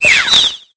Cri de Goupilou dans Pokémon Épée et Bouclier.